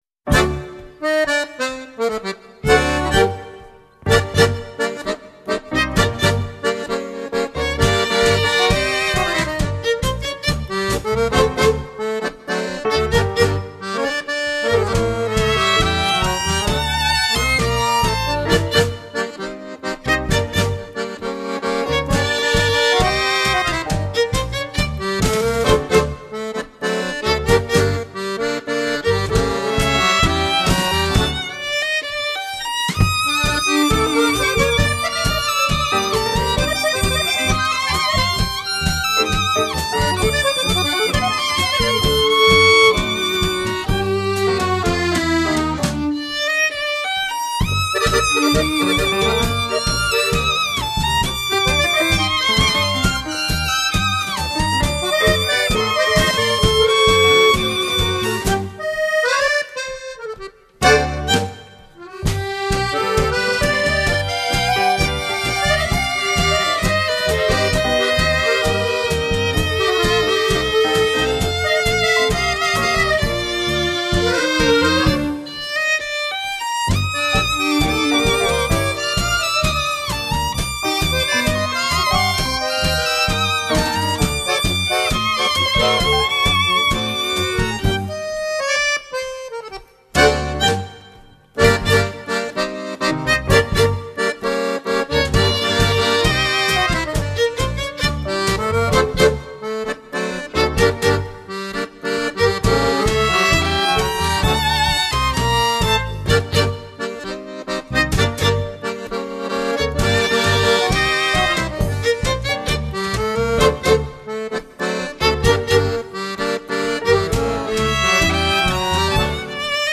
Ballroom Dance